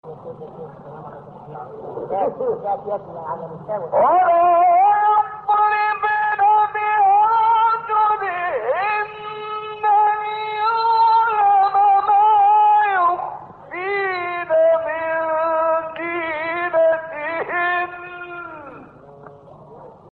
هشت فراز در مقام «سه‌گاه» با صوت محمد عمران
گروه فعالیت‌های قرآنی: فرازهایی در مقام سه‌گاه با صوت شیخ محمد عمران را می‌شنوید.